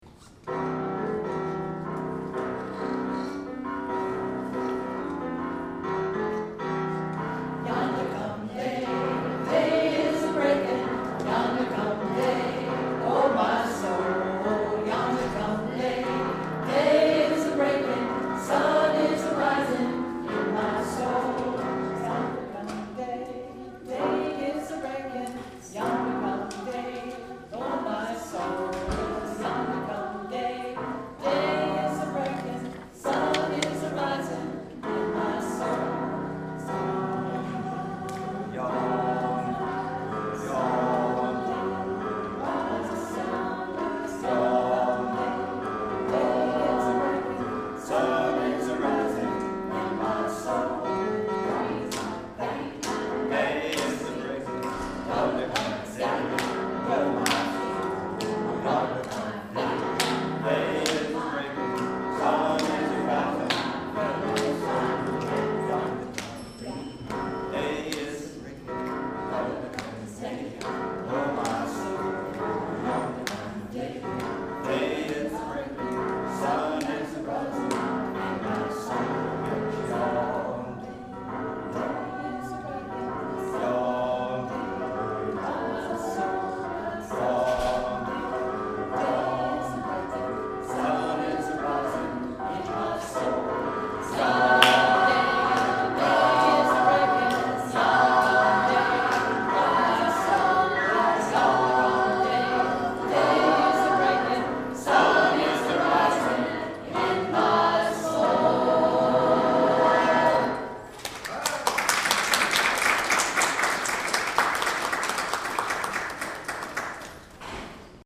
For 10 years, Tangy Voce, a chorus made up of current and retired faculty and staff, has offered its members an opportunity to grow as musicians and gain confidence as singers.